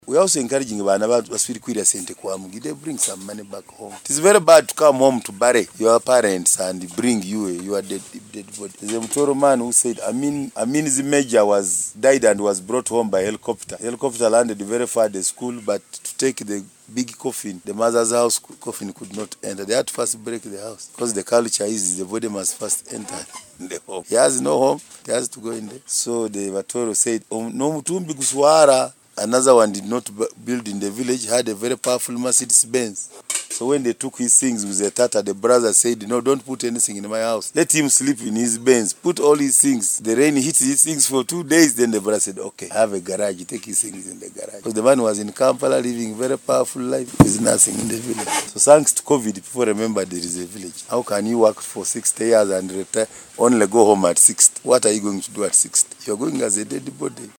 He addressed residents during preparations for the launch of the Biiso War Memorial Business Park , scheduled for December 26th to 31st, 2025 , alongside a week-long exhibition. Mukitale expressed concern that many Buliisa-born professionals—especially those employed in the oil and gas sector—invest heavily in other districts and on other luxary items while neglecting development in their own communities. He urged them to channel their salaries into productive ventures that will uplift themselves and Buliisa at large.